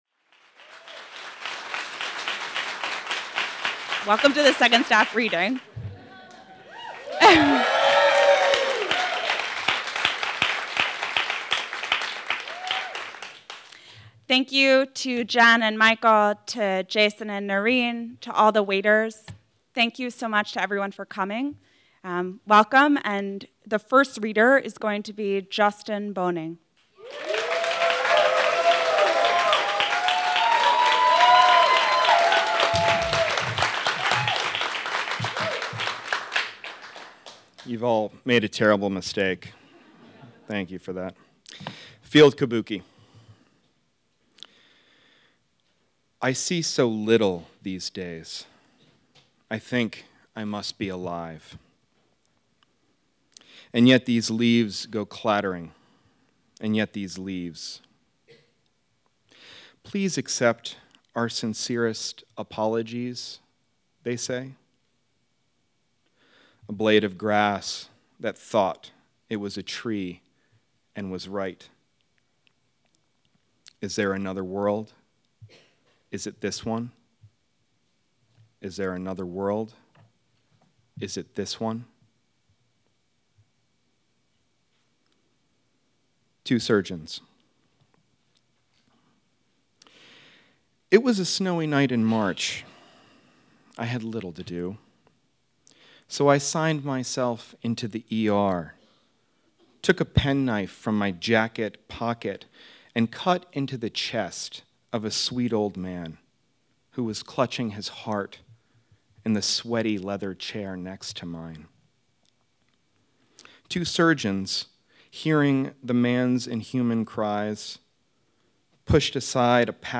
Along with attending workshops and lectures; working various jobs to support the conference; staff also were invited to read in the Little Theater. This is a recording from the second night of staff readings.